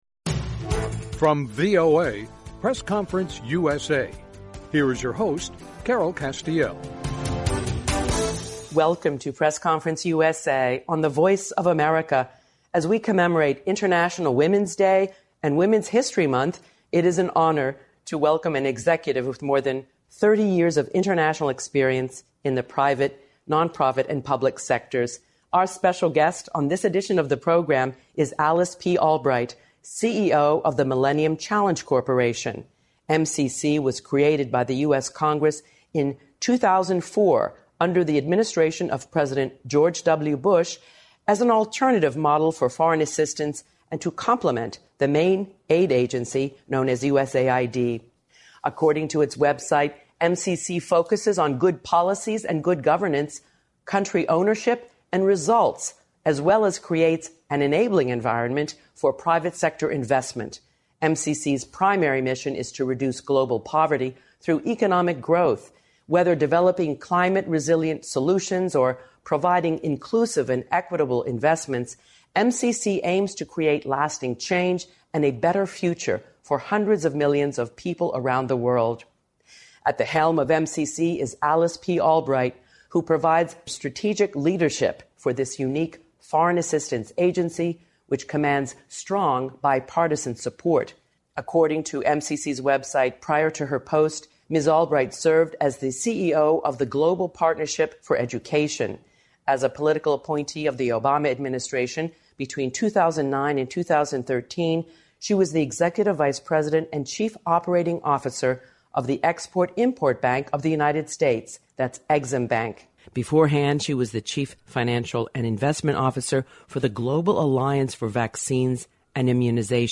A Conversation with Alice P. Albright, CEO, Millennium Challenge Corporation